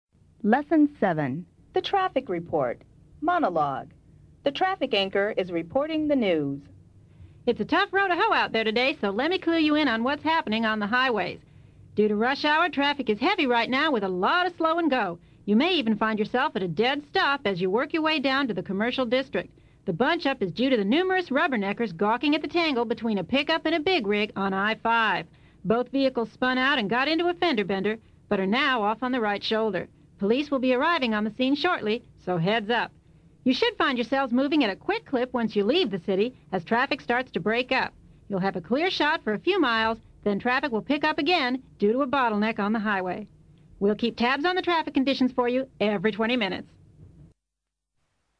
The Traffic Report
The traffic anchor is reporting the news.